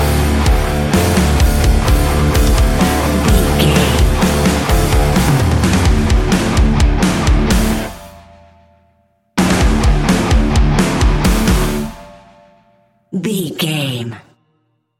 Ionian/Major
hard rock
heavy metal
instrumentals